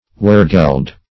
wehrgeld - definition of wehrgeld - synonyms, pronunciation, spelling from Free Dictionary
wehrgeld - definition of wehrgeld - synonyms, pronunciation, spelling from Free Dictionary Search Result for " wehrgeld" : The Collaborative International Dictionary of English v.0.48: Wehrgeld \Wehr"geld`\, Wehrgelt \Wehr"gelt`\, n. (O. Eng.